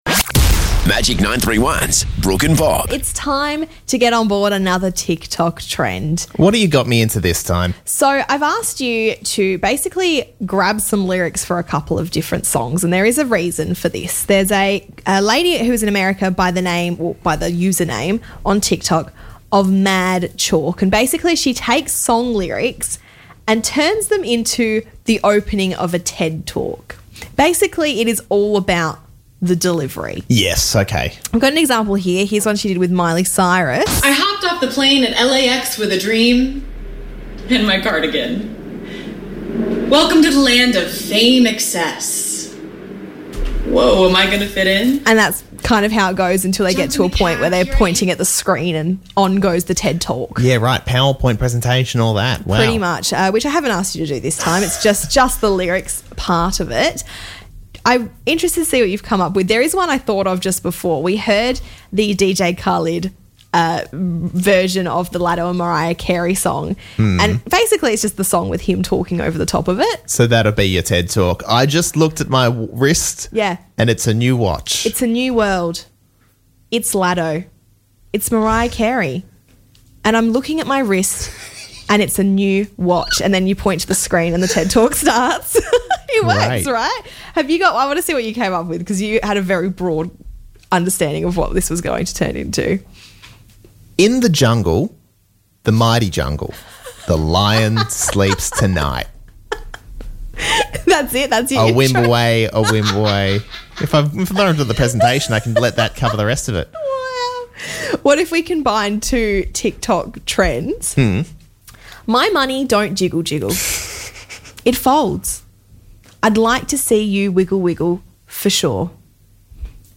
took to performing song lyrics as if they were delivering a keynote.